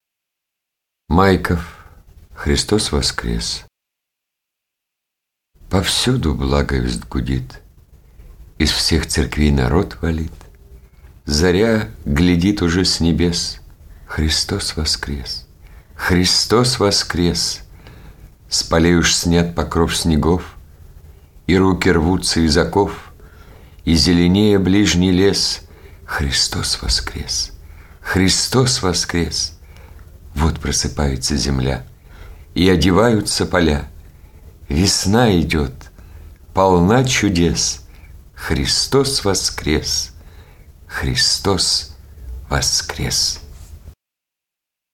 Христос воскрес! - аудио стих Майкова А. Стих прославляет воскресение Христа.